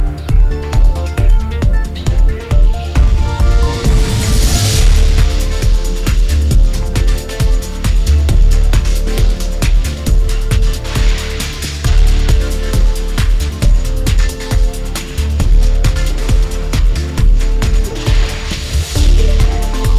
audio-continuation audio-to-audio music-generation
generated_audio_diffusion.wav